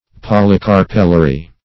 Search Result for " polycarpellary" : The Collaborative International Dictionary of English v.0.48: Polycarpellary \Pol`y*car"pel*la*ry\, a. (Bot.)
polycarpellary.mp3